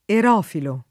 [ er 0 filo ]